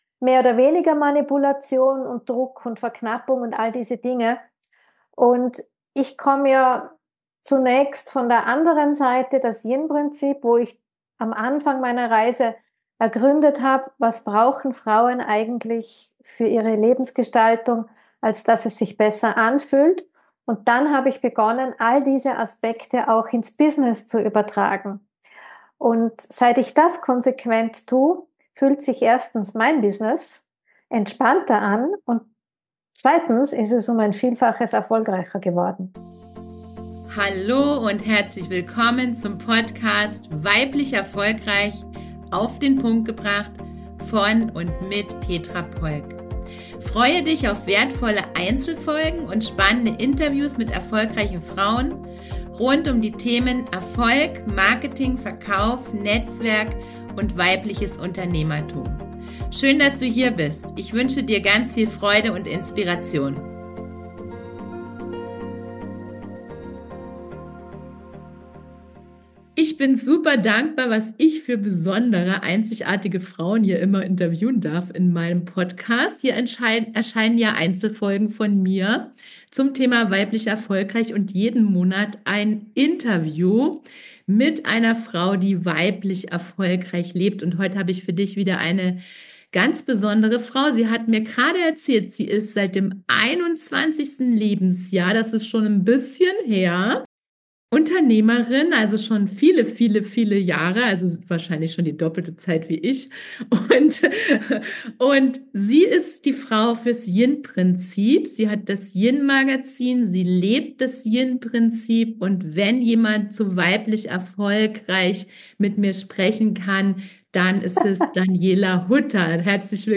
Heute habe ich wieder eine ganz besondere Interviewpartnerin für dich